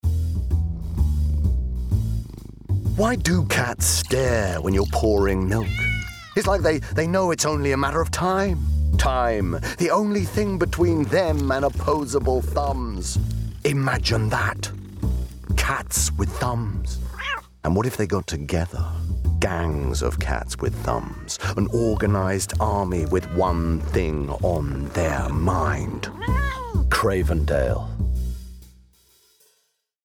Murray has a deep, expressive and brilliantly theatrical tone to his voice.
• Male
• Standard English R P